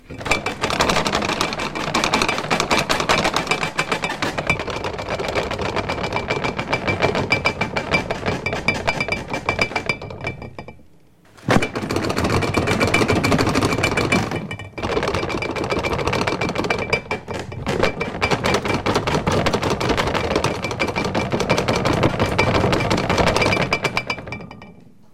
大地震
描述：大地震的声音。灵感来自于新西兰的坎特伯雷地震。 这是一个虚构的地震，由各种家用物品单独录制的隆隆声、砰砰声和摇晃声组成。
标签： 破坏 地震 震动 撞击 地震 震级大 灾难 恐怖
声道立体声